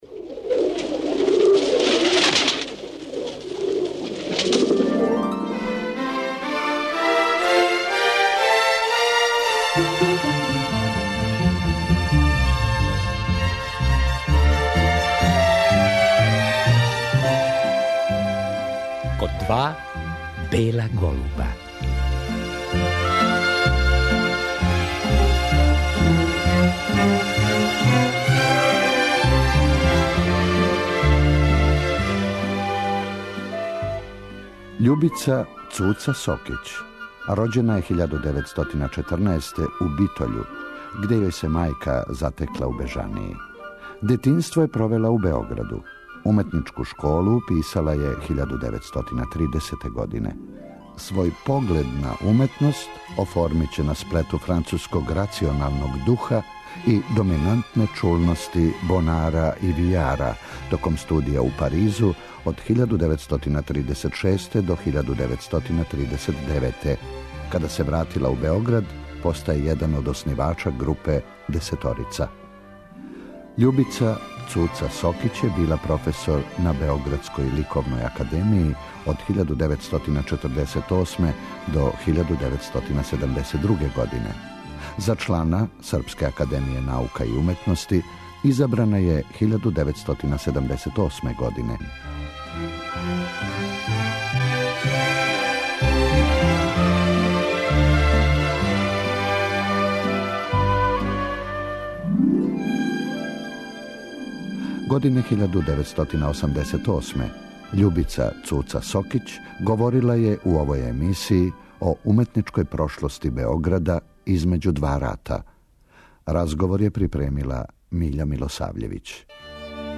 наша сликарка говори о свом детињству и школовању, о свом животу и ликовном стваралаштву.